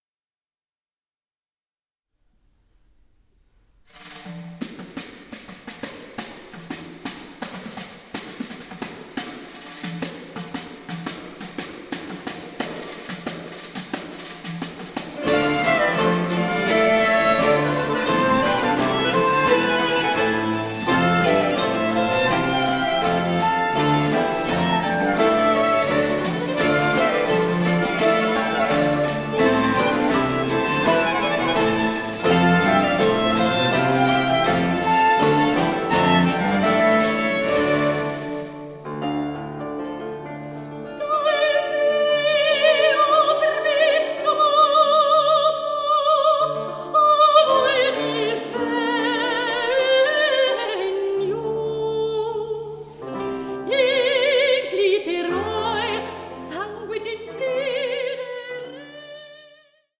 and her last soprano recording